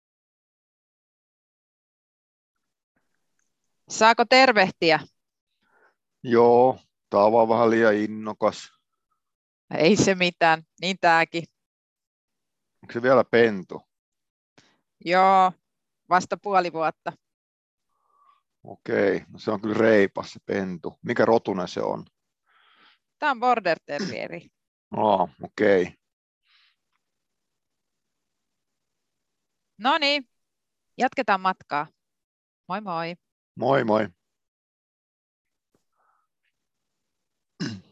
dialogi-2.mp3